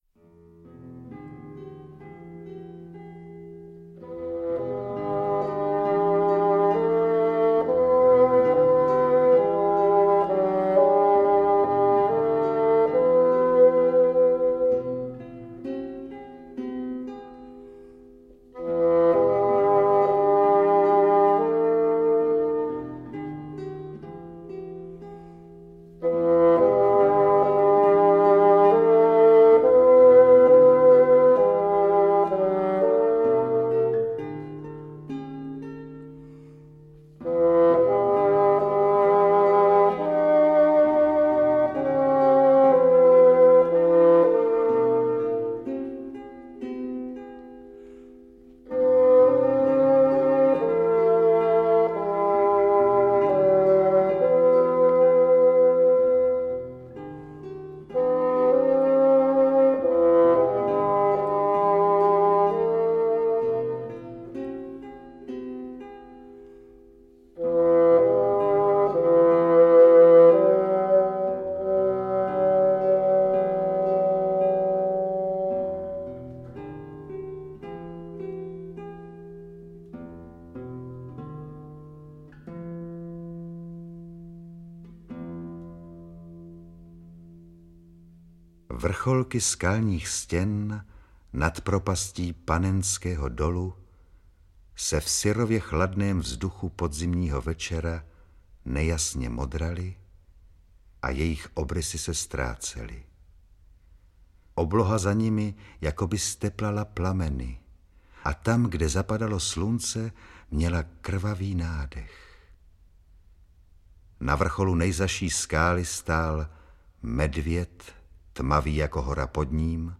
Interpreti:  Zdeněk Buchvaldek, Jiřina Petrovická
AudioKniha ke stažení, 2 x mp3, délka 1 hod. 4 min., velikost 58,4 MB, česky